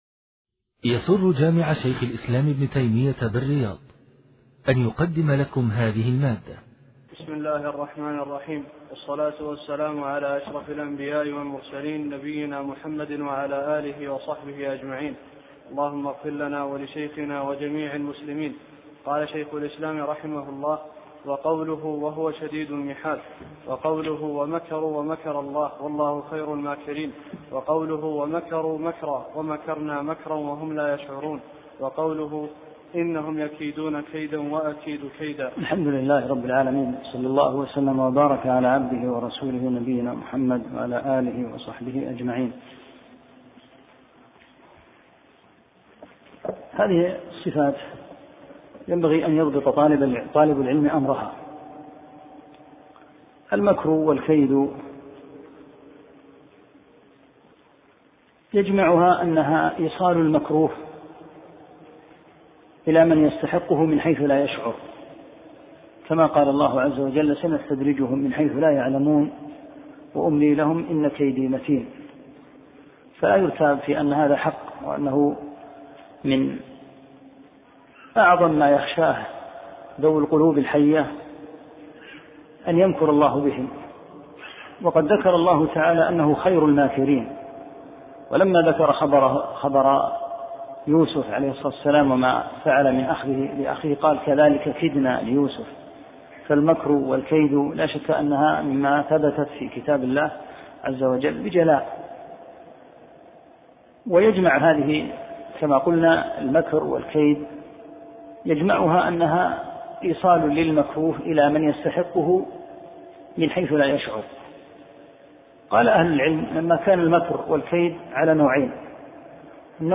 4- الدرس الرابع